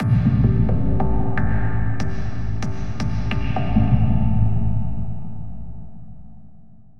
Processed Hits 12.wav